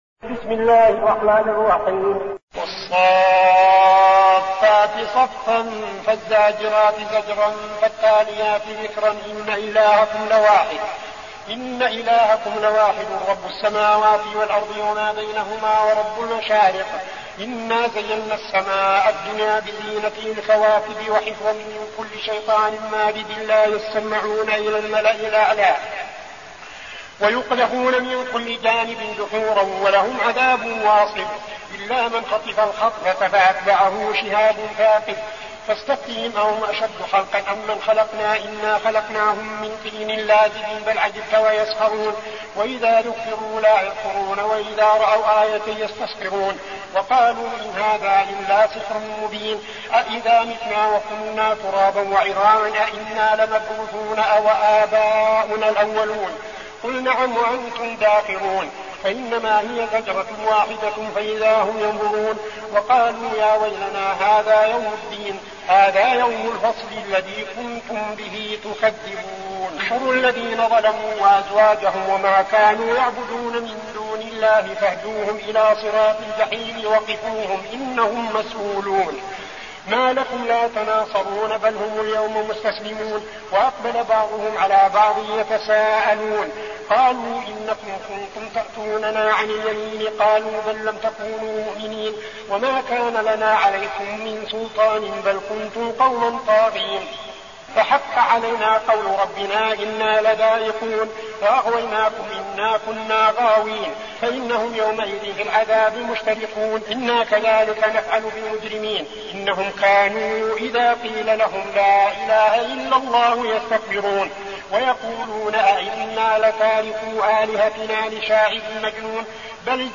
المكان: المسجد النبوي الشيخ: فضيلة الشيخ عبدالعزيز بن صالح فضيلة الشيخ عبدالعزيز بن صالح الصافات